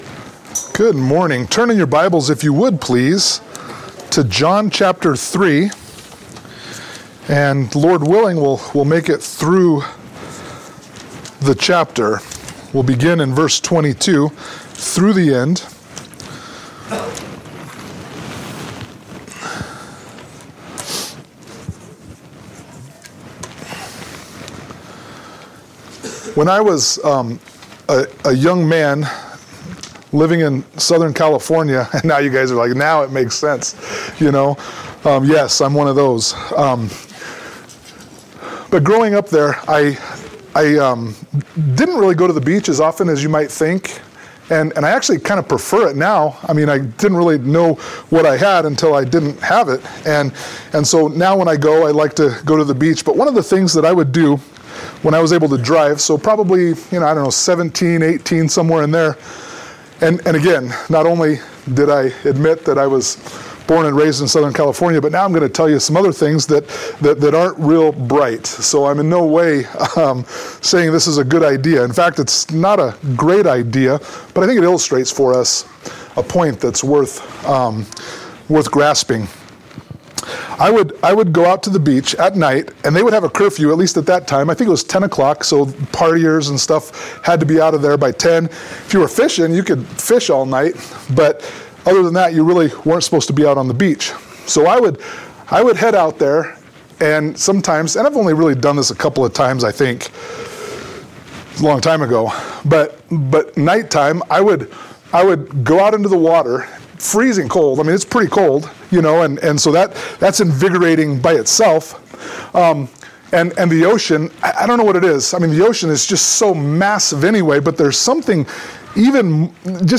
John 3:22-36 Service Type: Sunday Morning Worship « Christmas 2023 John 4:1-42